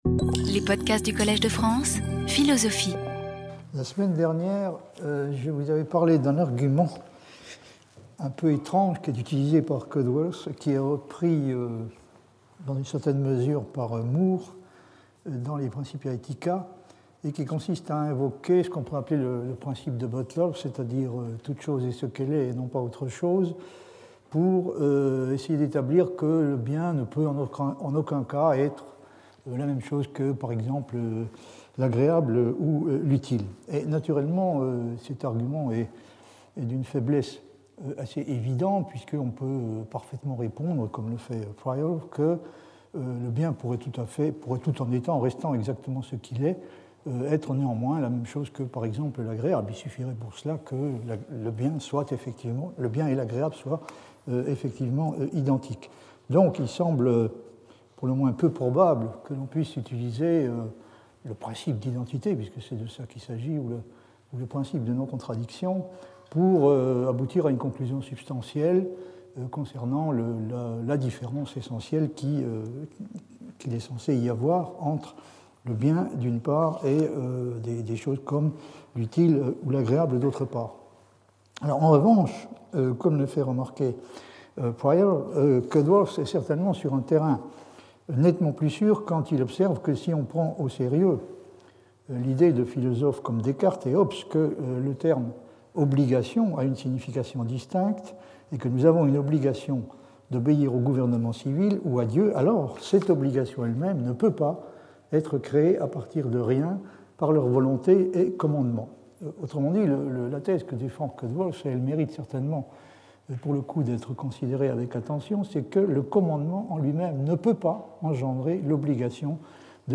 Jacques Bouveresse Professor at the Collège de France
Lecture